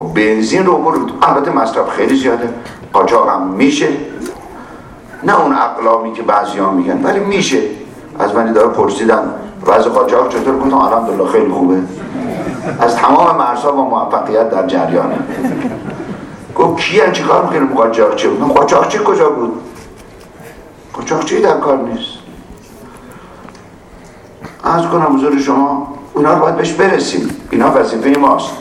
به گزارش خبرنگار اقتصادی خبرگزاری تسنیم، امروز بیژن زنگنه وزیر نفت کشورمان در خصوص قاچاق سوخت به ویژه بنزین و گازوئیل در جمع خبرنگاران گفت: تا زمانی که اختلاف قیمت در سوخت عرضه داخل و اطراف ایران ادامه دارد، قاچاق سوخت هم ادامه خواهد داشت.
زنگنه همچنین در بخشی از سخنان امروز خود در کنگره نفت و نیرو اظهار داشت: مصرف بنزین خیلی زیاد است و قاچاق هم می شود؛ نه به اندازه و اقلامی که برخی می گویند، اما قاچاق صورت می گیرد.